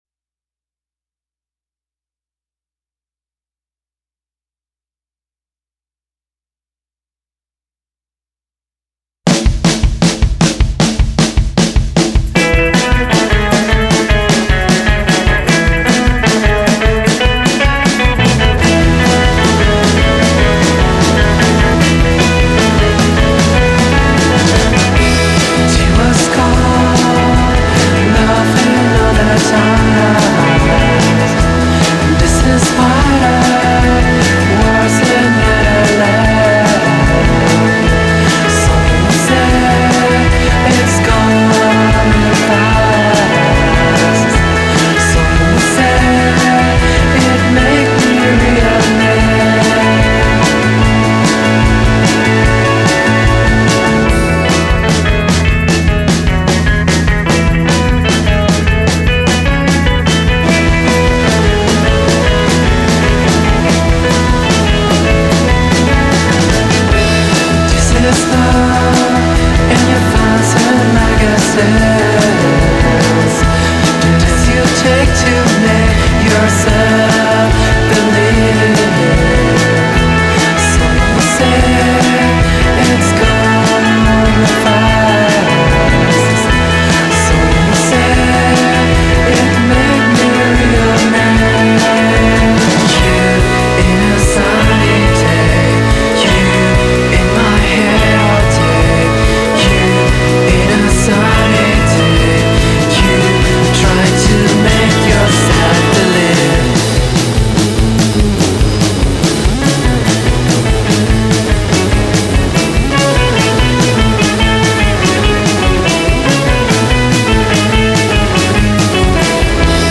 duo spagnolo